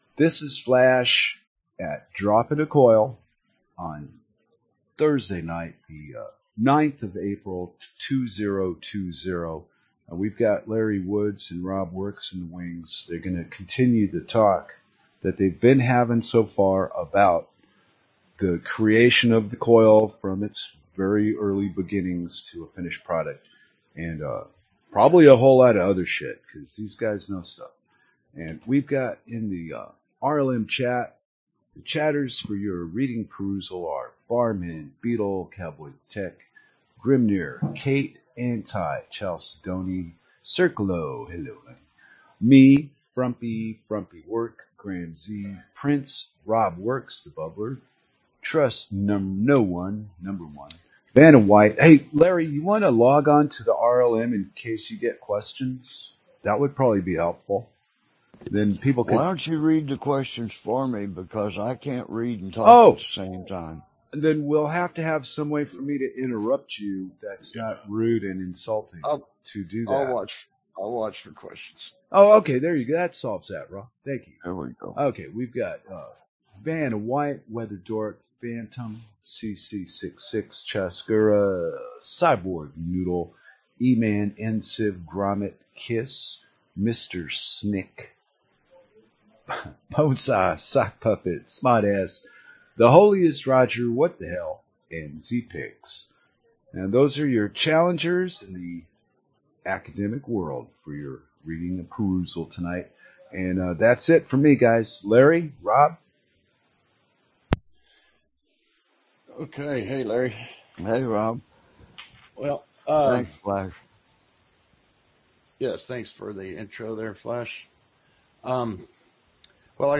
Genre Talk